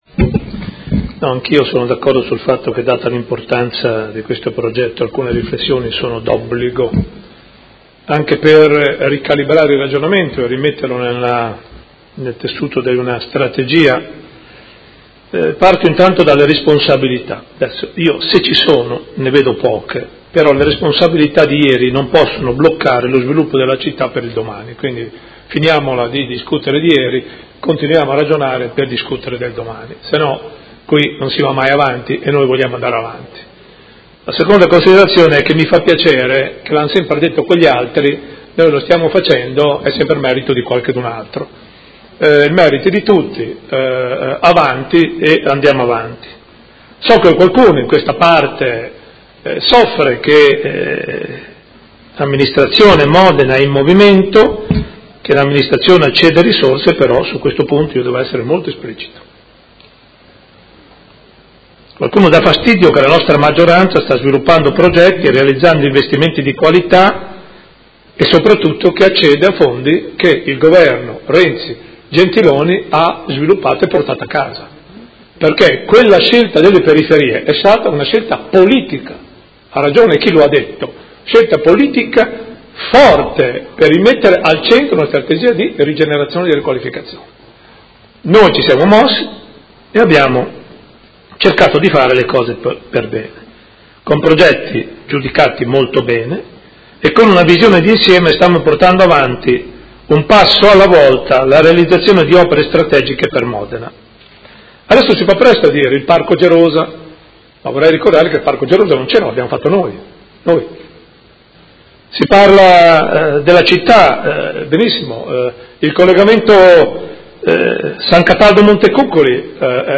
Seduta del 25/05/2017 Dibattito su Delibera Linee di indirizzo per il riordino funzionale e morfologico dell’ Area urbana a Nord di Modena “Fascia ferroviaria” – Approvazione Masterplan e su Ordine del giorno 80690 avente per oggetto: Progetto Periferie, rigenerazione e innovazione.